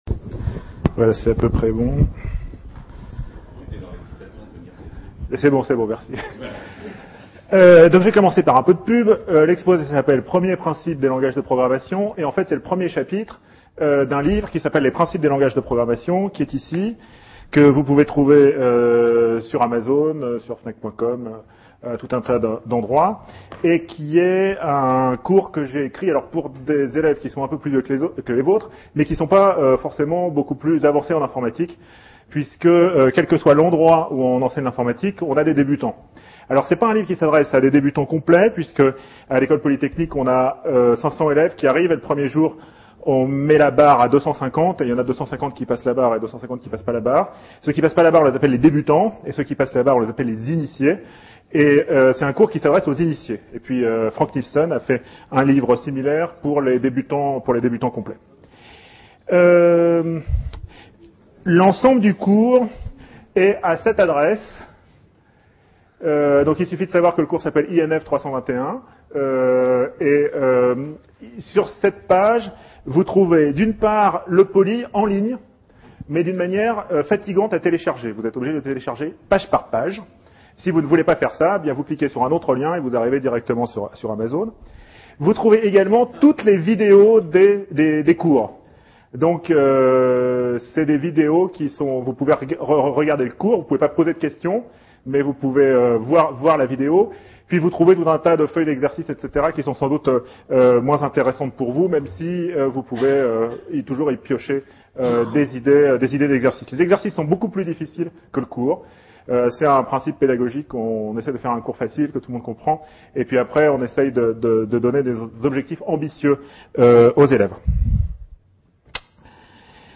Il donne les fondamentaux qui permettent aux enseignants d'enseigner l'algorithmique en seconde ou de faire connaitre les objets numériques dans les enseignements d'option. Ce cours a été donné en juin 2009 et juin 2010 lors des journées de formation à l'informatique organisées par l'INRIA à destination des professeurs de mathématiques d'Ile de France.